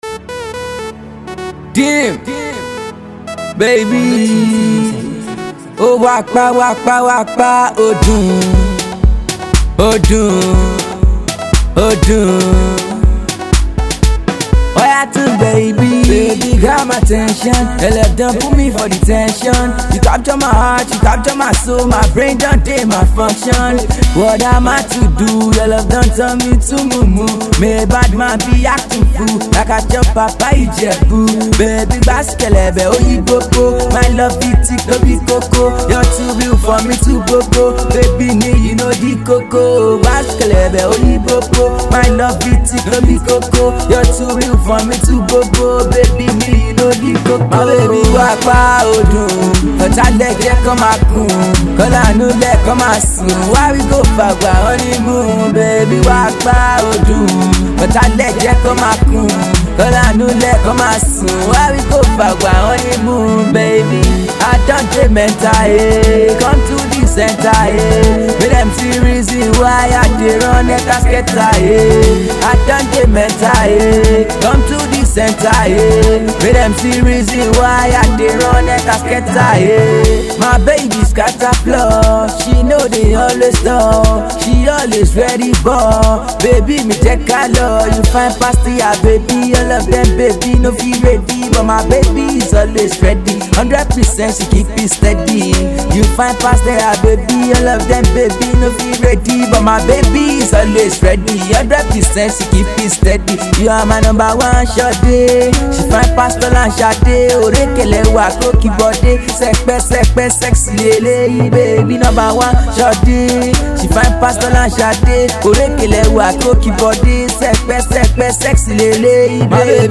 Indigenous Pop